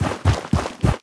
biter-walk-big-4.ogg